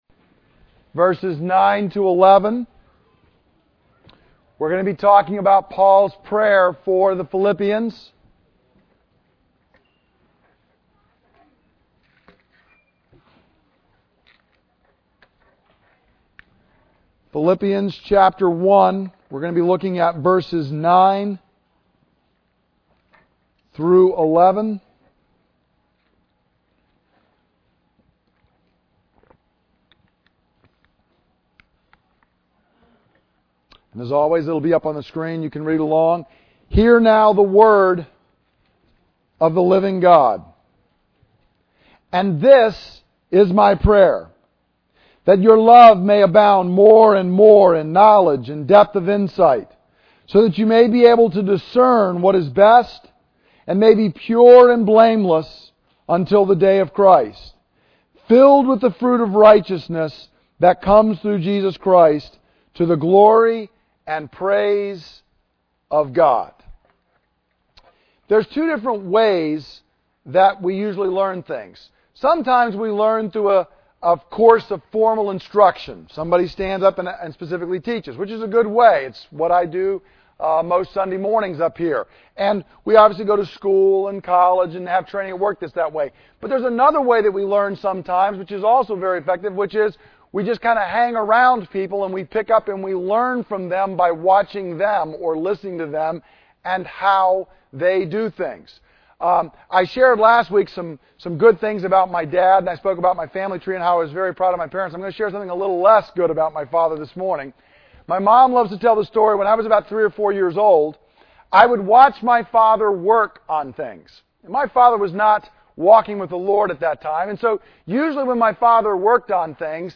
Psalm 9:7-11 Listen to the teaching Look at the teaching outline December 30